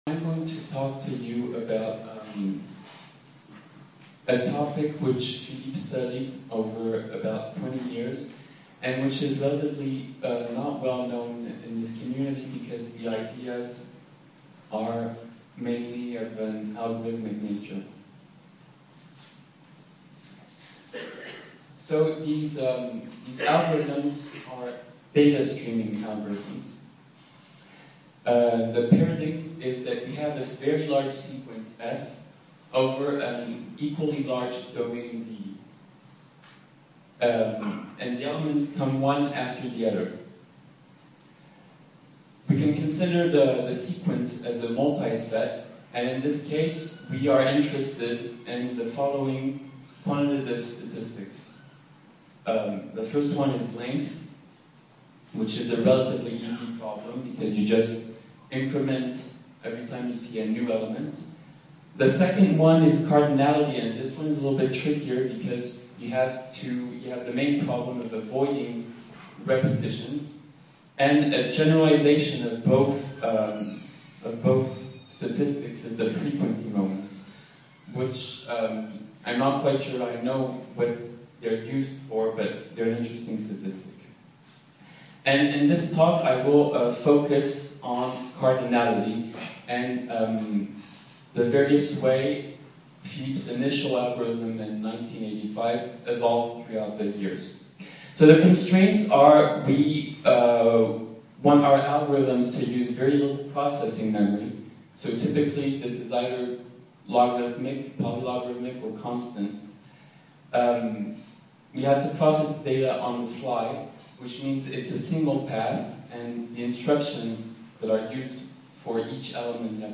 invited colloquium
Date December 16, 2011 Time 11:30 AM – 12:00 PM Location Paris, France Event Philippe Flajolet and Analytic Combinatorics (PFAC)